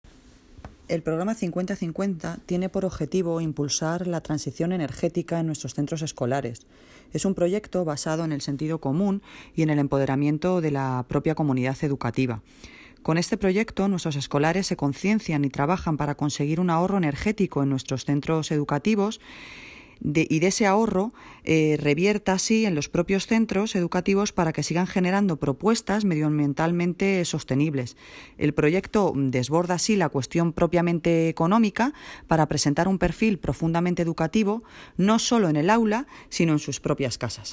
Audio - Noelia Posse (Alcaldesa de Móstoles) Sobre Programa 5050